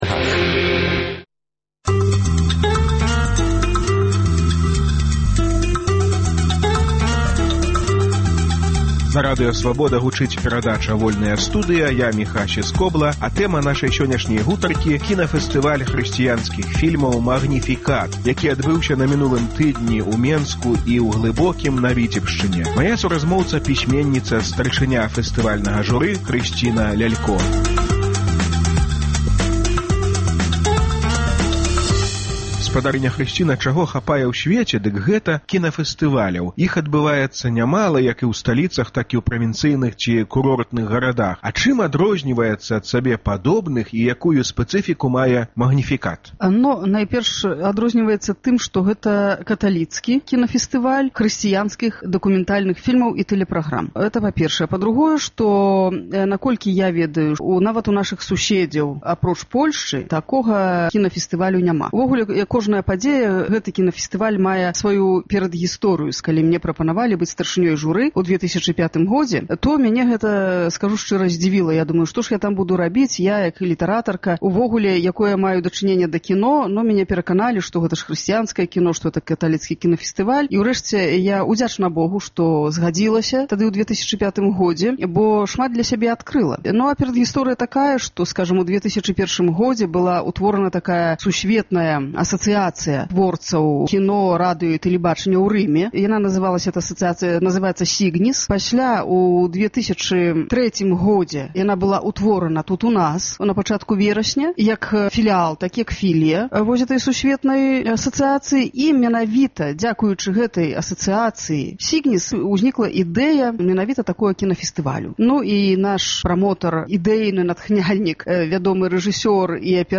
Чаму на кінафэстывалі "Магніфікат" быў зьняты з паказу фільм Аляксея Шэіна "Хрыстос забаронены"? Гутарка